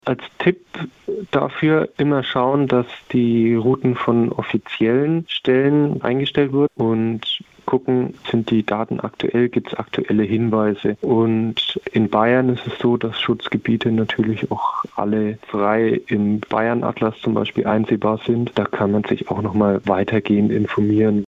Interview: Die Gefahr vom Wandern auf illegalen Wegen - PRIMATON